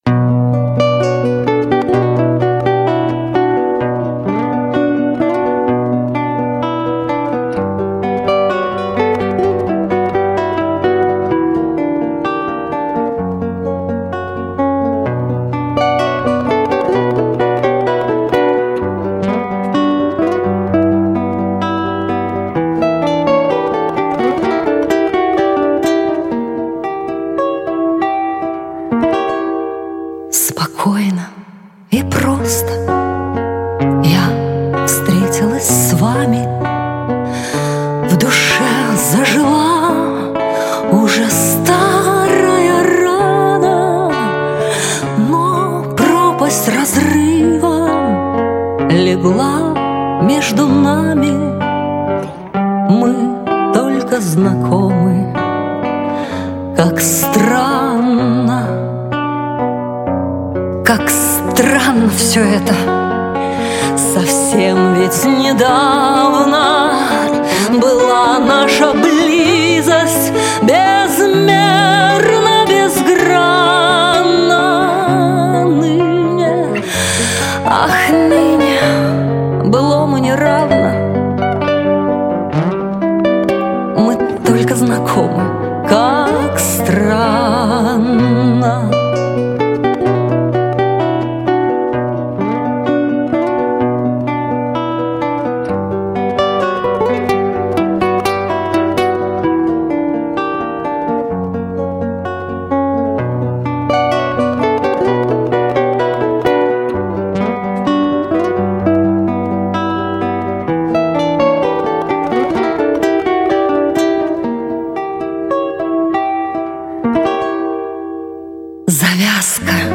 И для Завалинки есть у меня романс в тему: